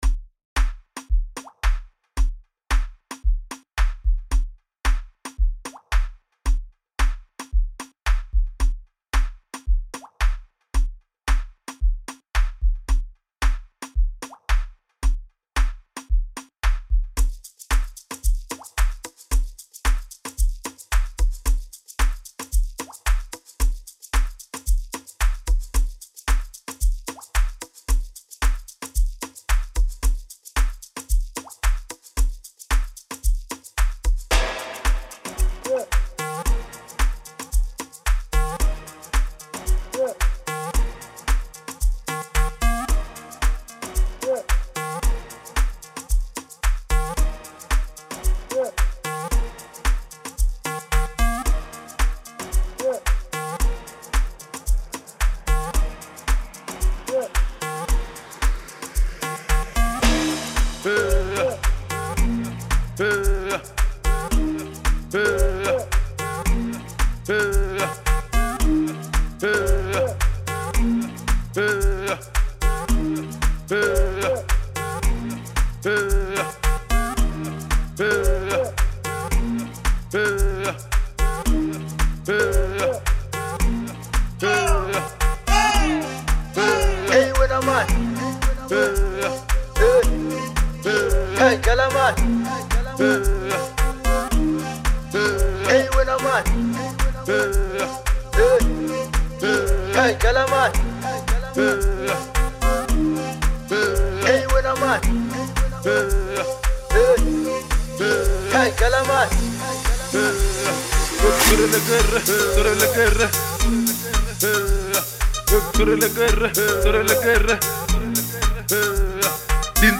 Enjoyable Vocals